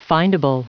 Prononciation du mot findable en anglais (fichier audio)
Prononciation du mot : findable
findable.wav